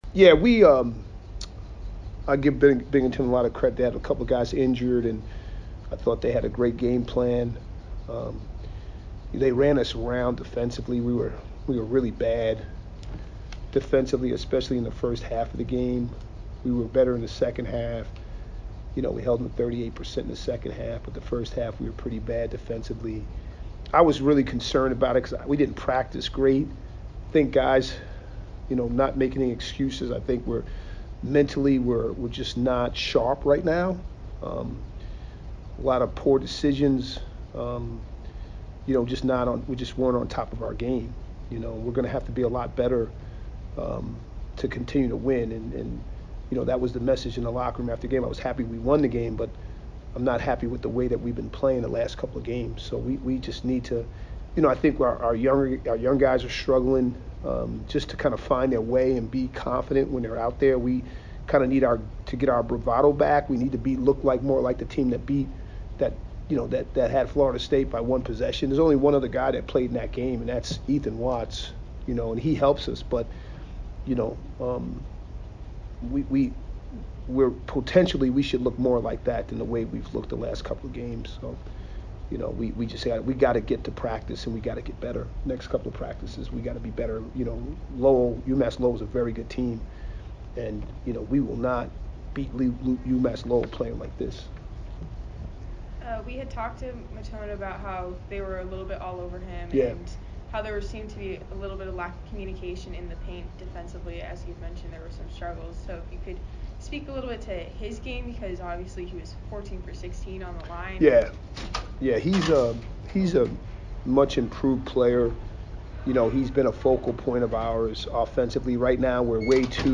Binghamton MBB Press Conference (12-4-21) - Boston University Athletics